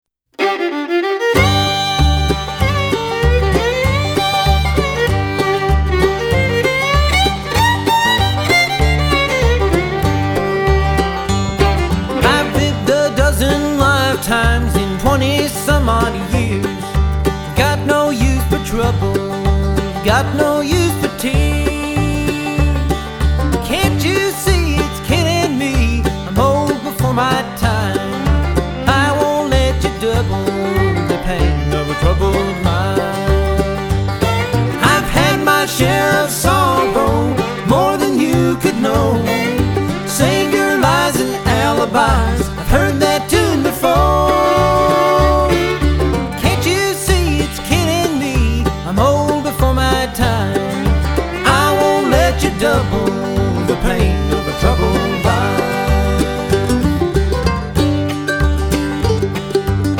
錄音效果當然不在話下
吉他與斑鳩琴充滿清亮透明的質感